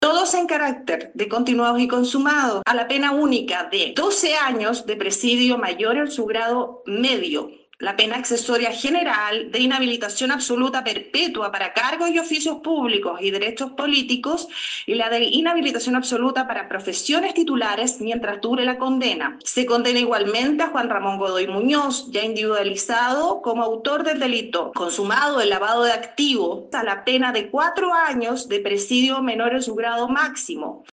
Escuchemos el dictamen de la jueza:
JUEZA.mp3